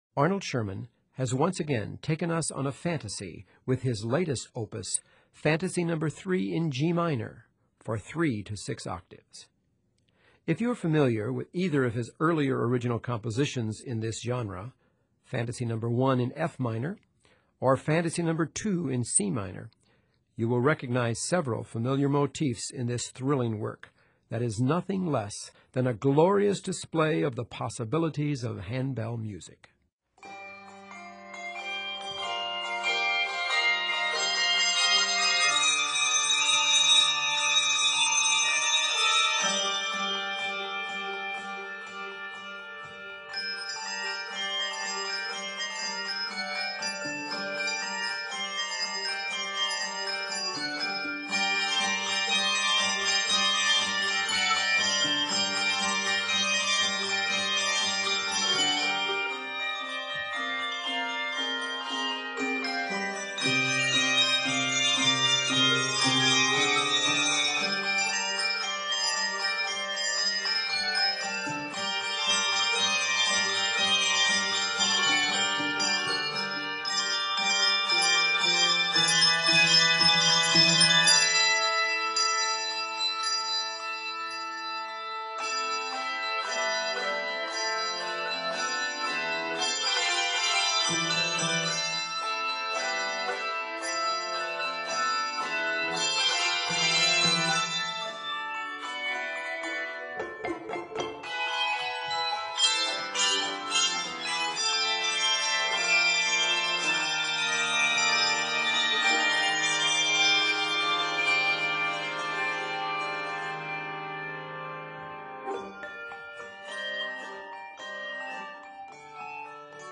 Watch for multiple techniques and key changes.